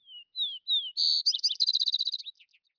sparrow1.wav